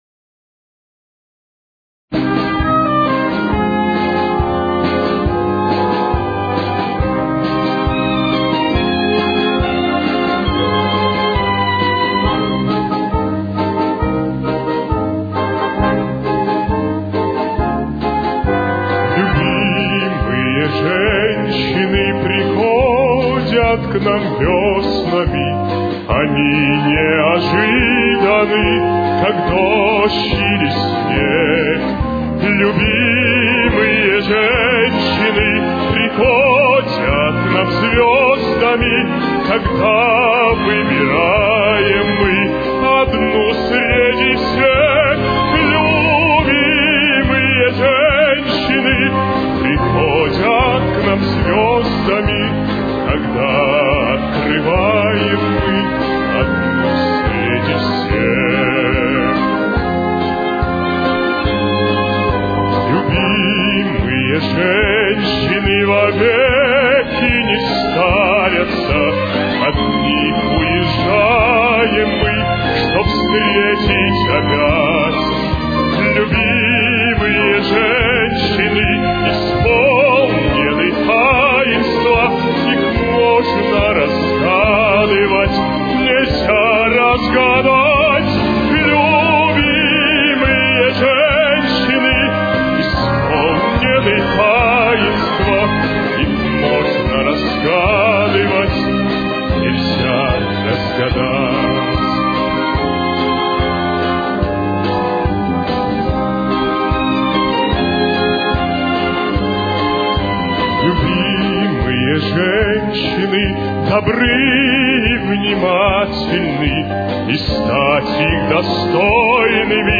с очень низким качеством (16 – 32 кБит/с)
Темп: 146.